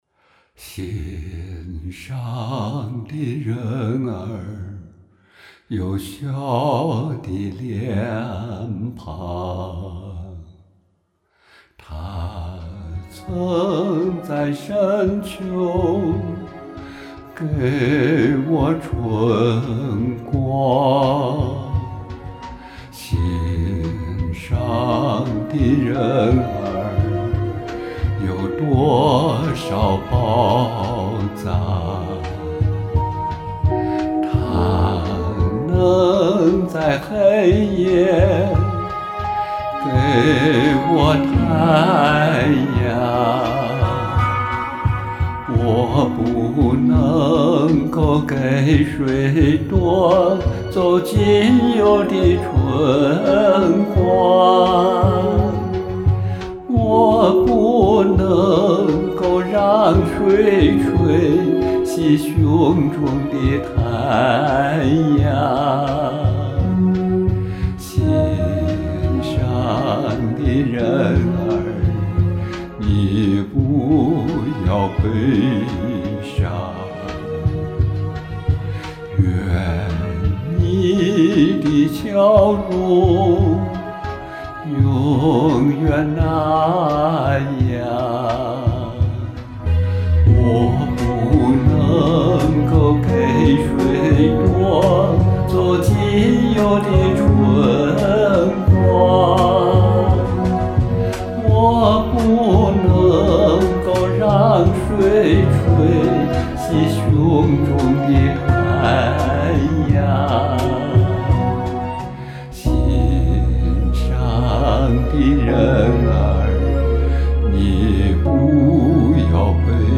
唱得投入！
低沉的歌声带给人沉痛之感
好唱，好深沉啊！